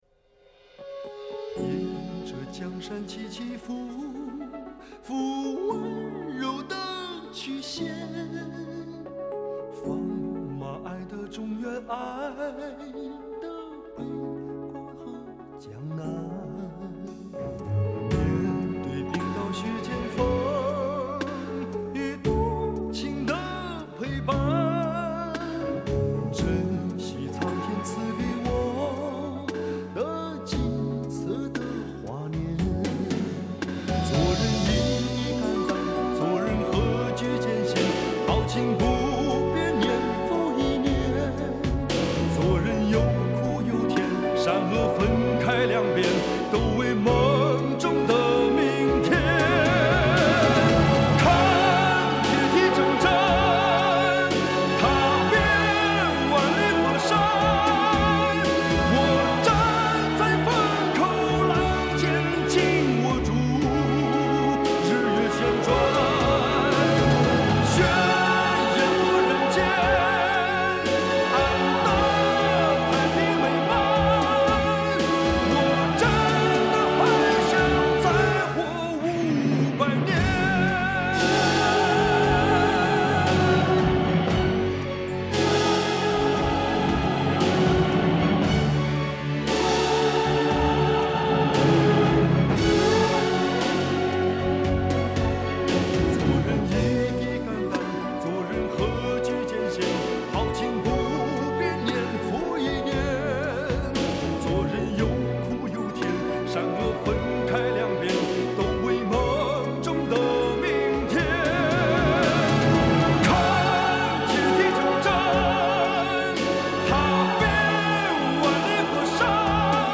背景音乐参考链接：